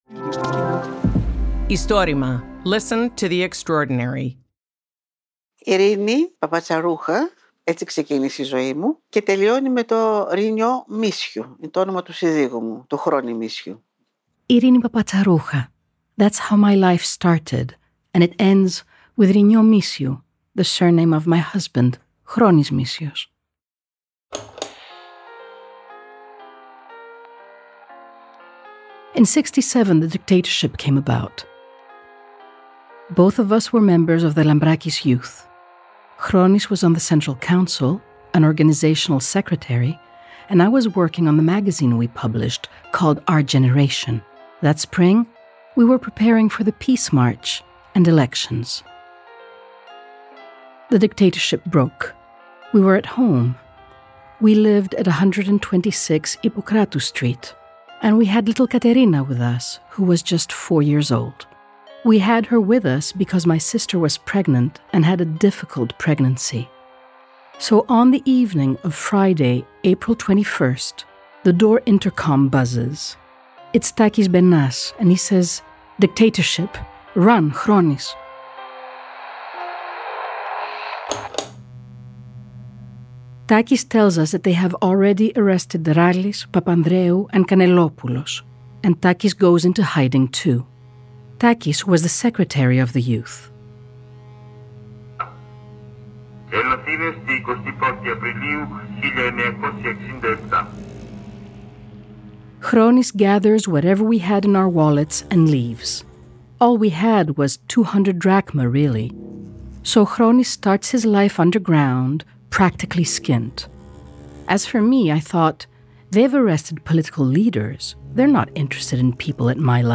Το Istorima είναι το μεγαλύτερο έργο καταγραφής και διάσωσης προφορικών ιστοριών της Ελλάδας.